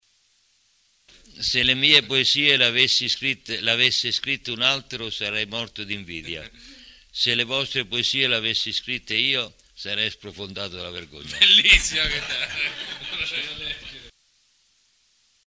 La registrazione è del 2004 ed è stata effettuata dai ragazzi dell’IPC di Cupra Marittima e dai loro insegnanti per Dream Radio Stream, la loro web radio, fu la prima in Italia di un istituto d’istruzione superiore. Le letture riguardano delle brevi poesie e alcuni aforismi.